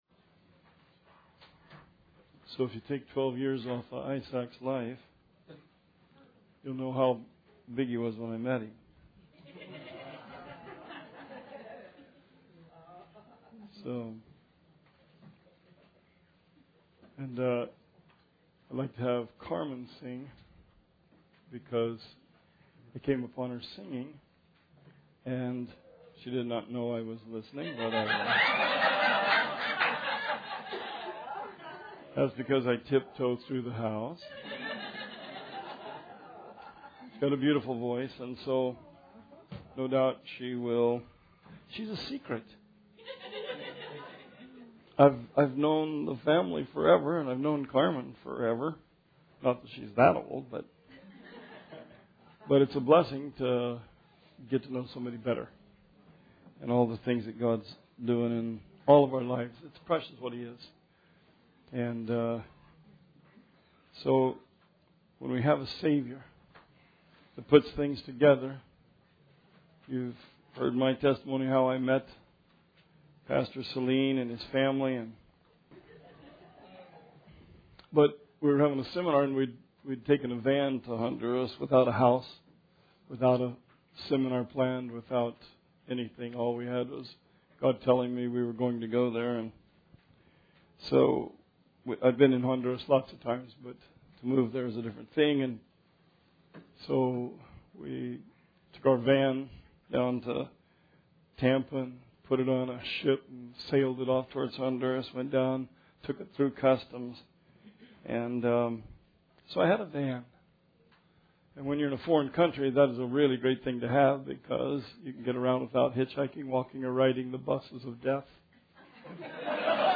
Sermon 10/23/16